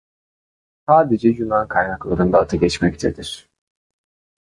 Pronúnciase como (IPA)
[juˈnɑn]